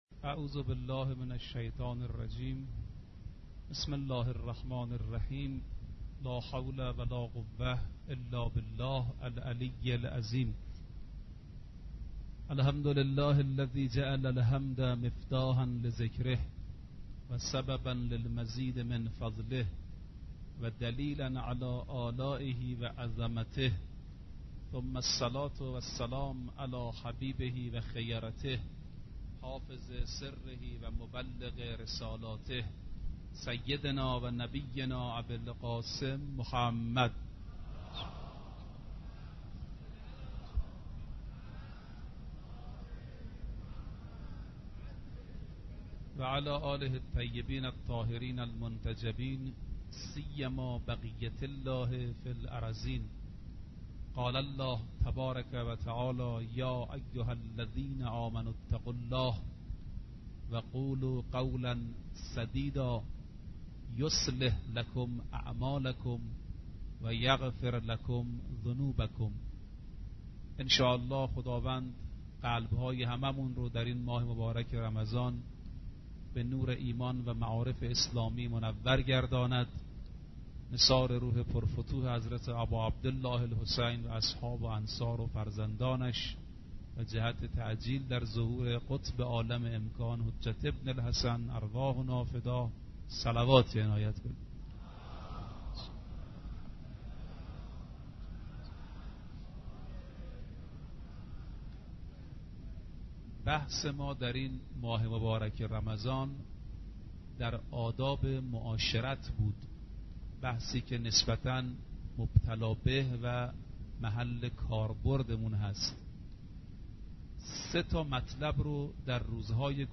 آرشیو ماه مبارک رمضان - سخنرانی - بخش یازدهم